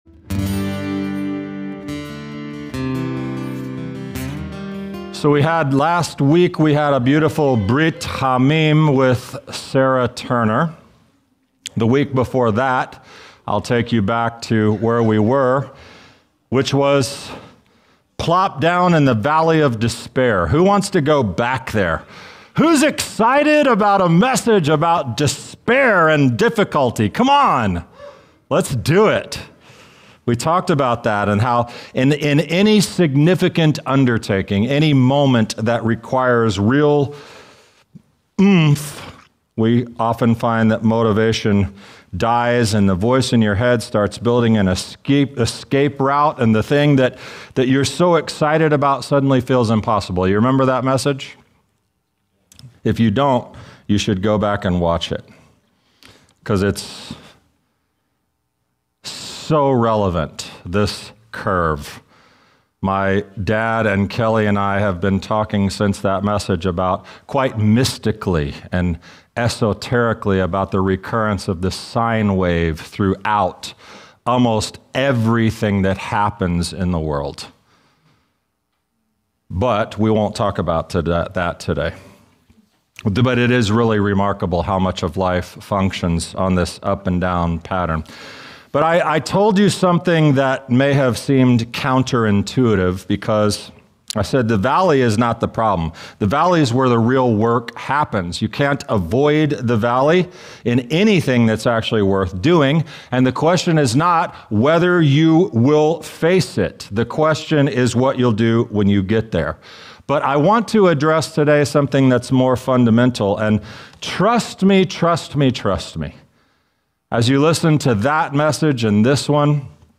In this teaching, we explore why new life in Messiah is about be